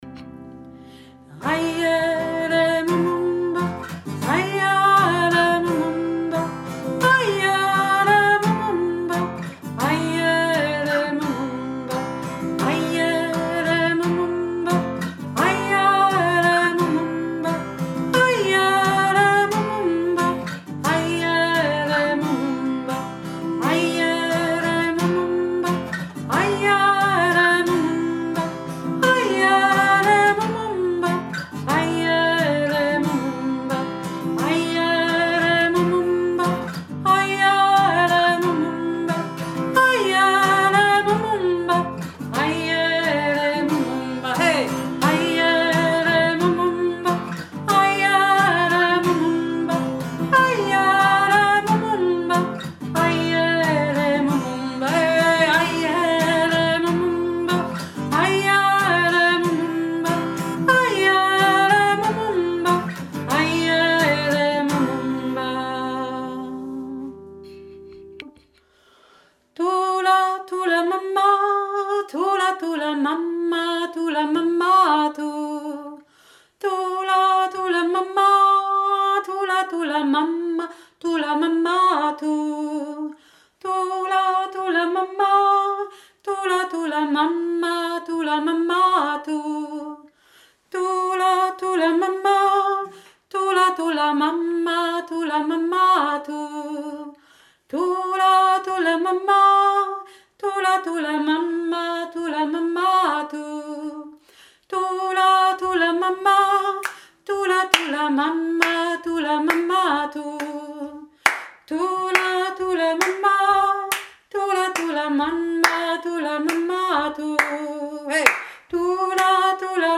AYELE MUMUMBA 2. Stimme - tief